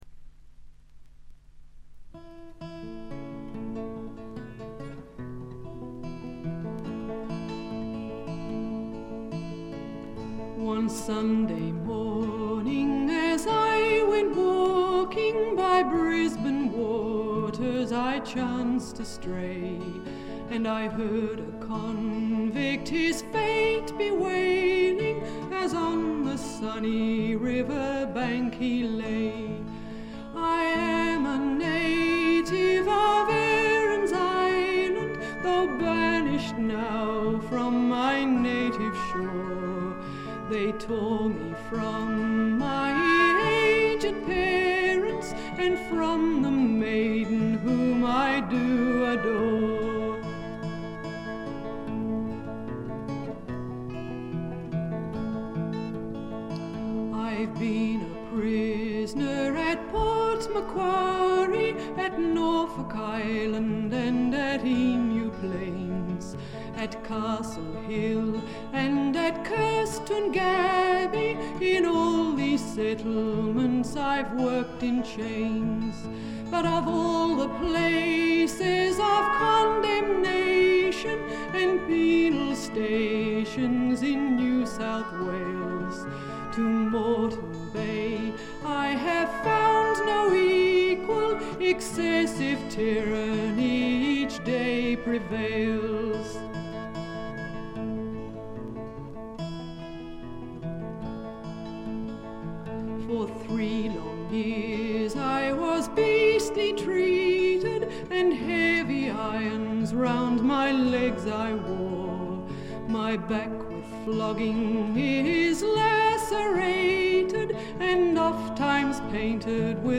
これ以外は軽微なバックグラウンドノイズやチリプチ程度。
試聴曲は現品からの取り込み音源です。